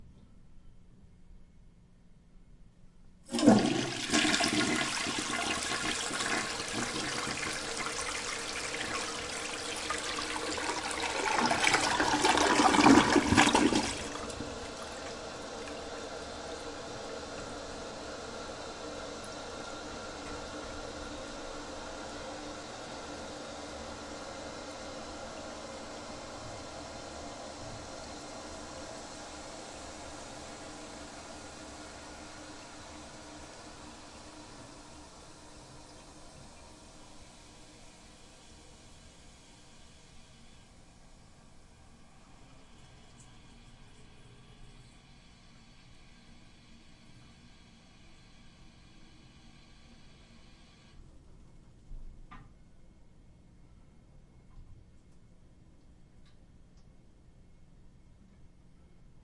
厕所冲水器（单体）
描述：厕所冲洗单声道
标签： 冲洗 冲洗厕所 厕所